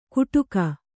♪ kuṭuka